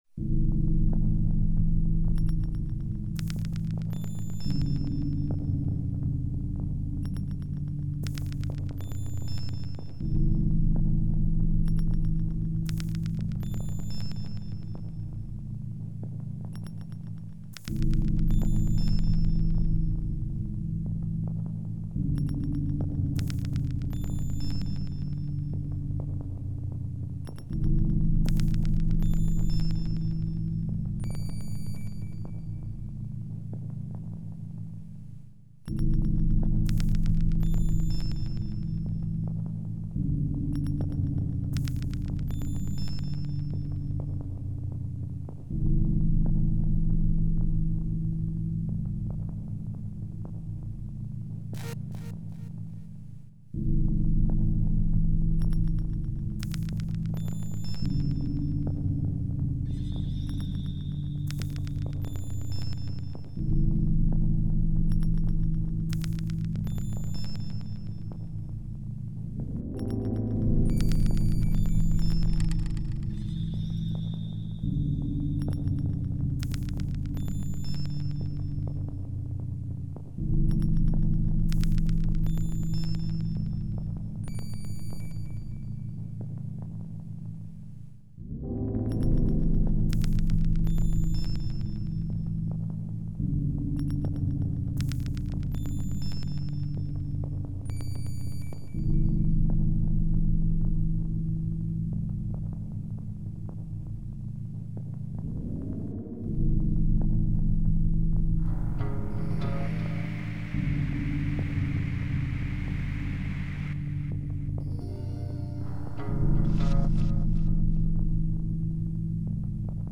游历于Indie Electronic与IDM之间的音乐风格， 和对悦耳动听的旋律及玲珑透彻的声响的追求